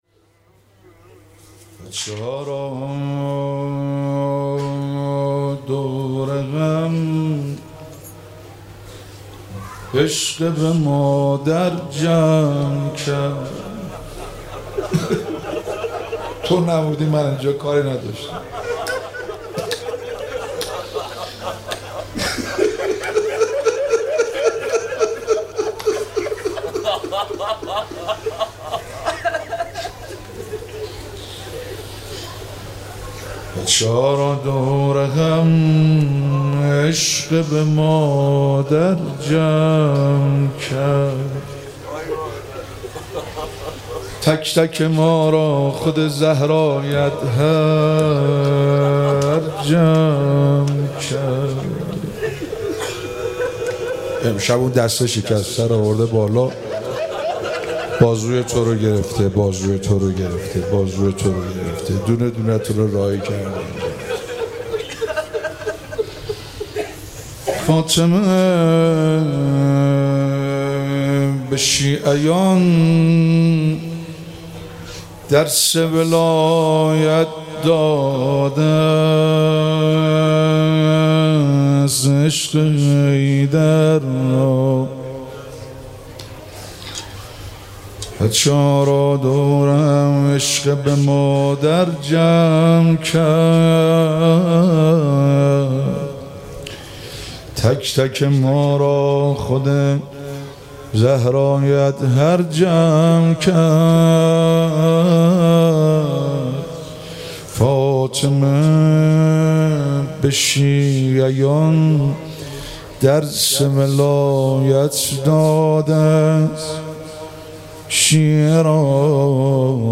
مراسم هفتگی
روضه - بچه ها را دور هم عشق به مادر جمع کرد